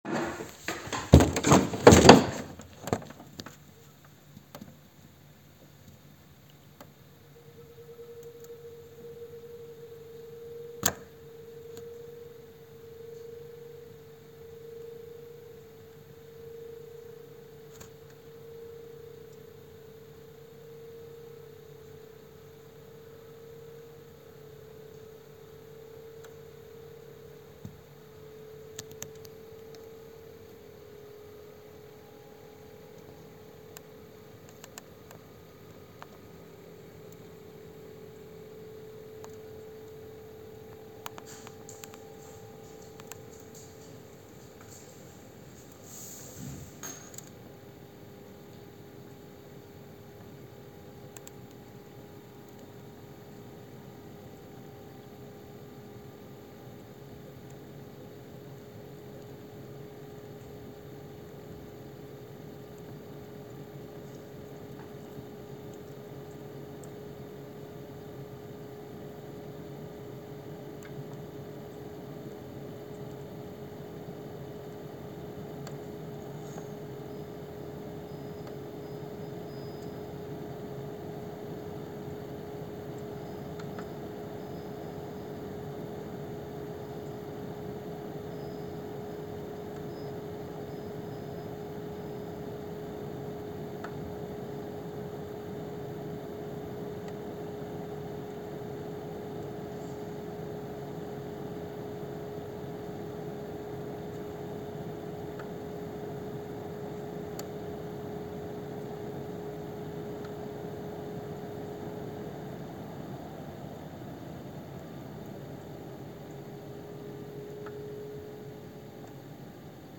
Sachant que le bruit se reproduit à chaque cycle de chauffe, c'est-à-dire toutes les 5-10 minutes, autant dire que ce n'est pas très agréable dizzy
Bruit climatiseur au démarrage
bruit-clim-1-.mp3